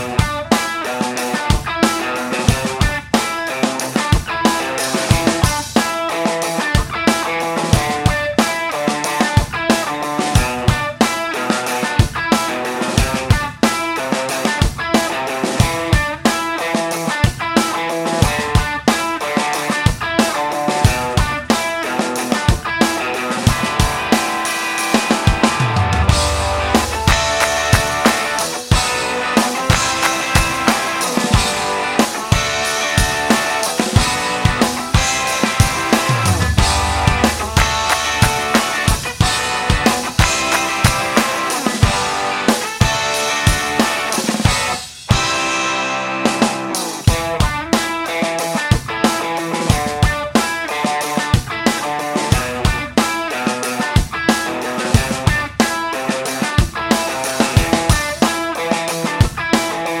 Minus Bass Rock 4:30 Buy £1.50